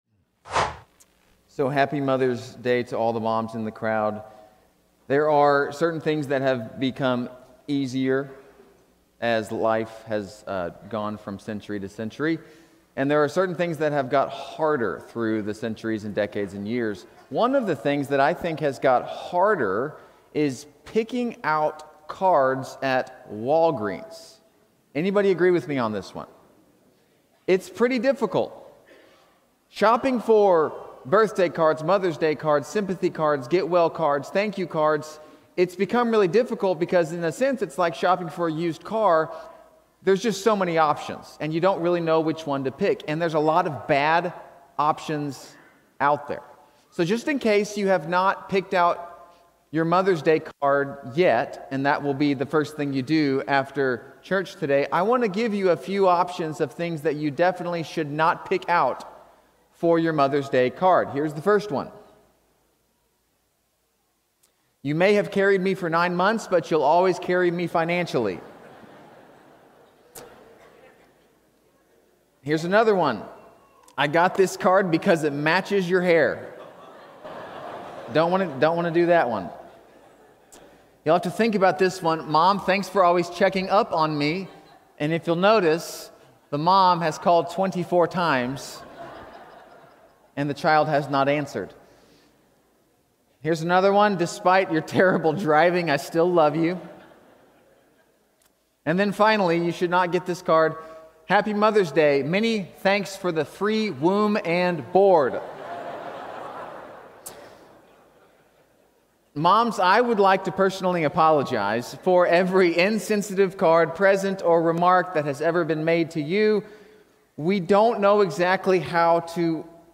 Series: Stand Alone, Sunday Morning